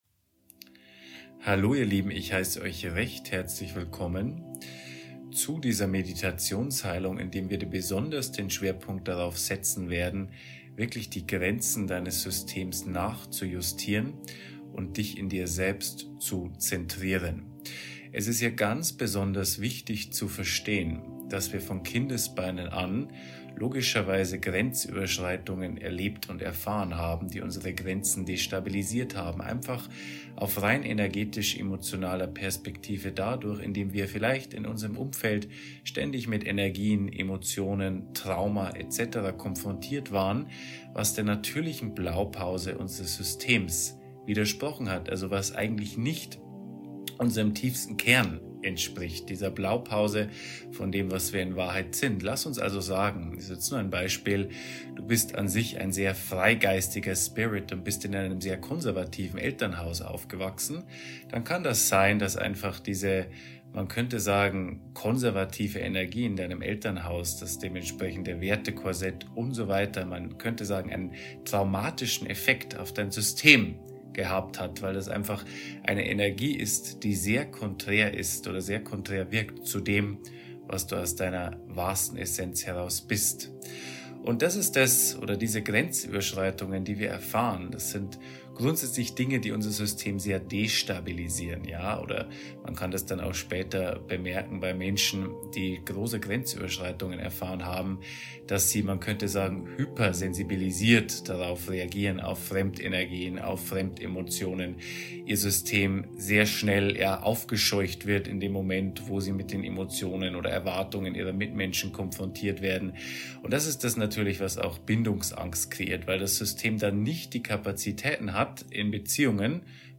Meditationsheilung Intensiv: Bei dir bleiben durch stabile Grenzen ~ Seelenrave: Not Another Healing Podcast